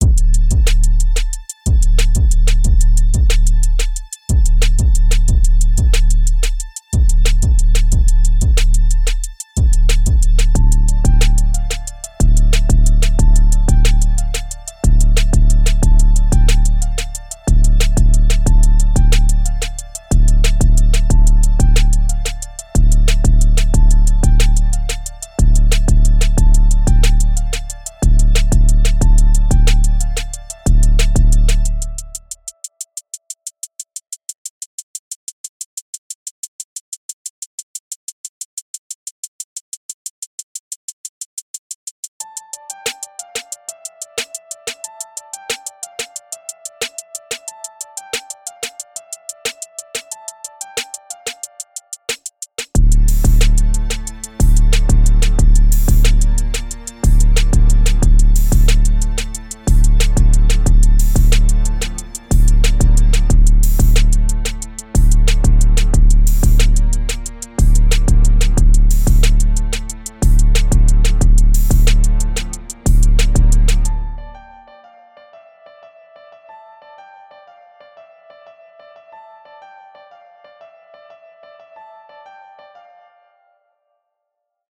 dark trap elements
This is a fairly easy Trap drum pattern.
808 Bass
Upright Piano
ominous string sound
Step 6: Layer the strings with a cello for effect